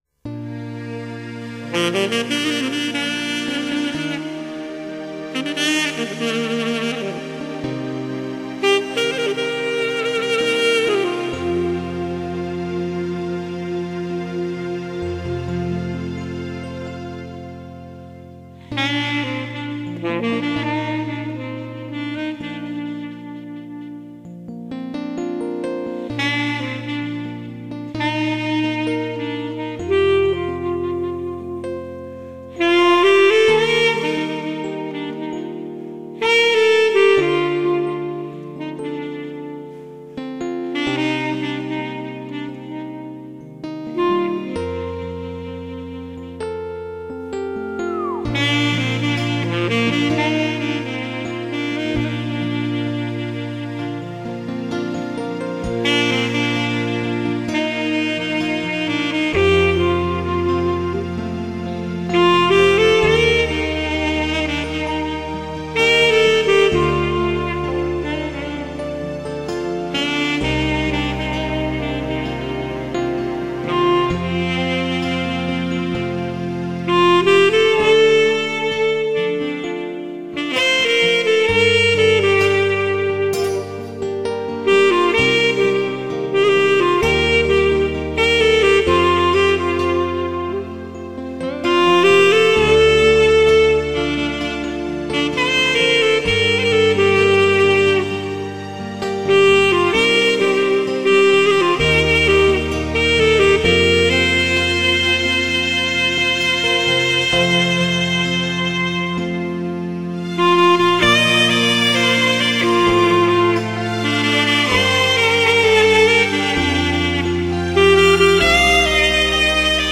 萨克斯独奏
吉它伴奏
马头琴伴奏
小提琴伴奏
双簧管伴奏
长笛伴奏
小号伴奏
二胡伴奏
琵琶伴奏
古筝伴奏
时尚萨克斯